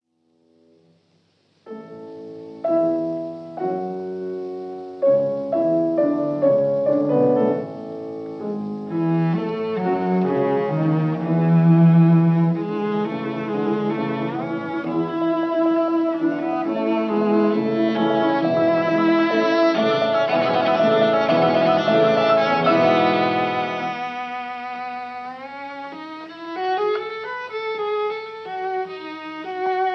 Allegro ma non troppo
piano